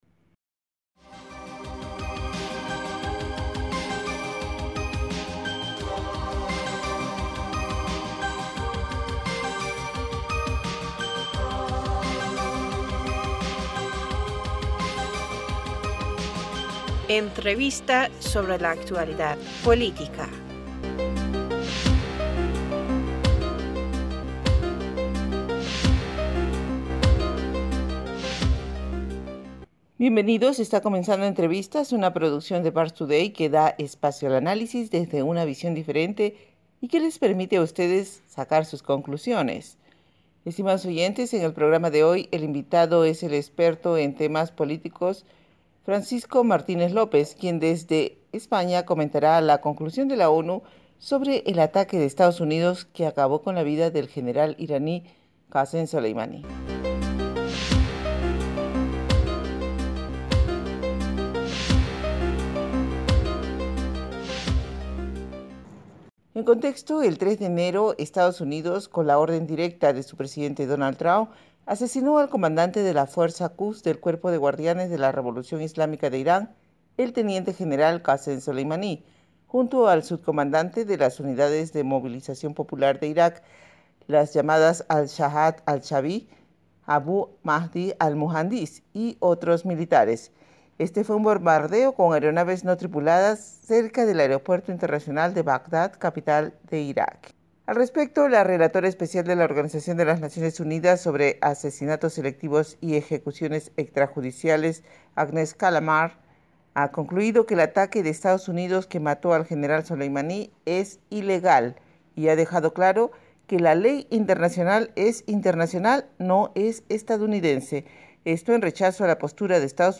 Bienvenidos está comenzando Entrevistas, una producción de Parstoday que da espacio al análisis desde una visión diferente y que les permite a ustedes sacar sus conclusiones.